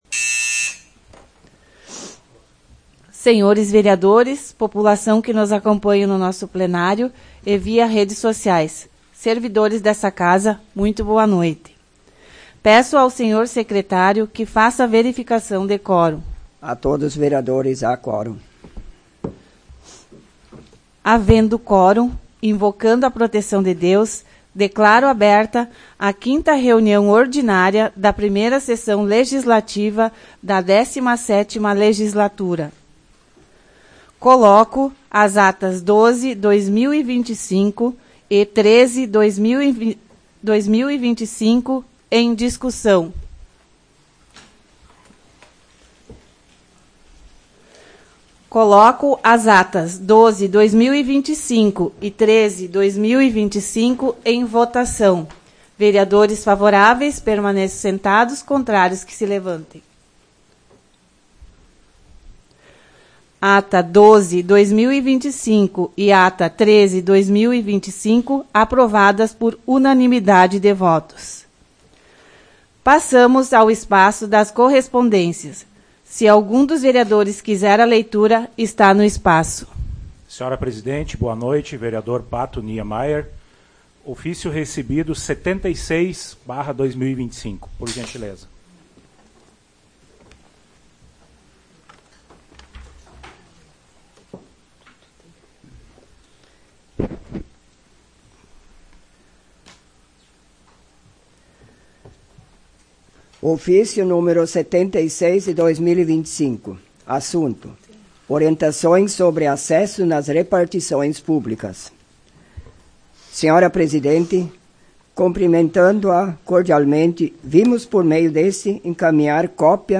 Áudio da 5ª Sessão Plenária Ordinária da 17ª Legislatura, de 07 de abril de 2025